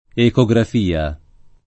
ecografia [ eko g raf & a ] s. f.